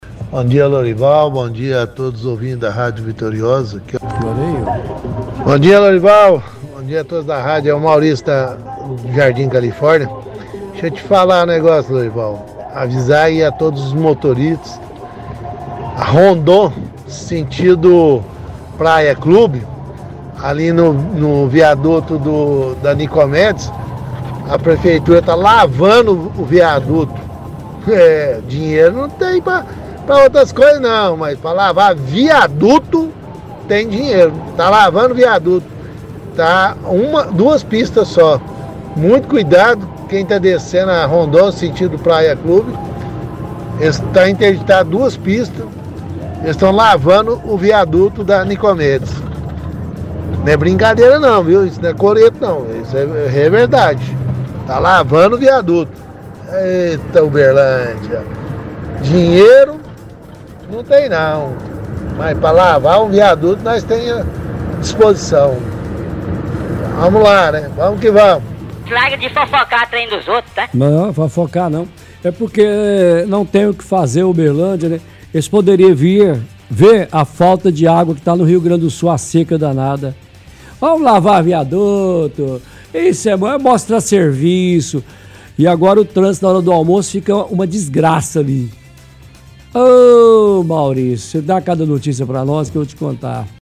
-Ouvinte alerta motoristas sobre interdições na via dizendo que a prefeitura está lavando o viaduto da av.Rondon Pacheco com a av.Nicomedes. Diz que para outras coisas a prefeitura não tem dinheiro, mas para lavar viaduto sim.